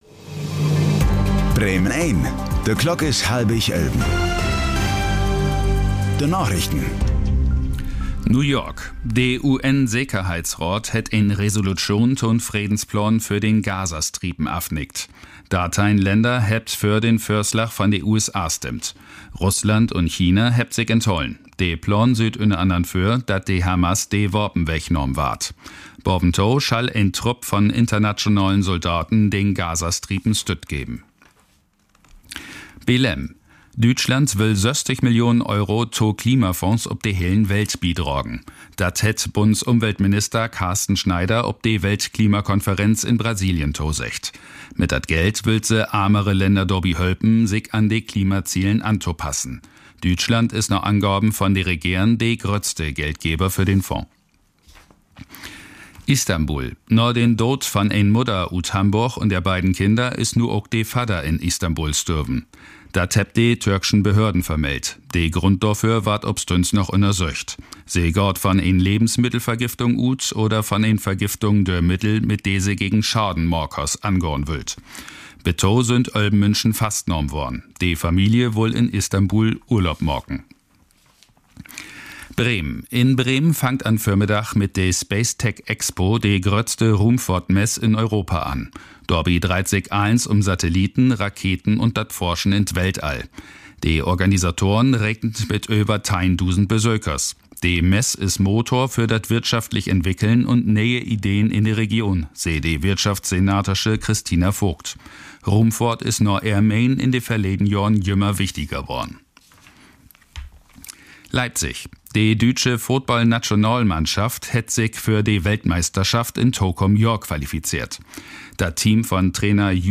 Aktuelle plattdeutsche Nachrichten werktags auf Bremen Eins und hier für Sie zum Nachhören.
… continue reading 1114 episodes # Tägliche Nachrichten # Nachrichten # Thu Apr 01 11:24:10 CEST 2021 Radio Bremen # Radio Bremen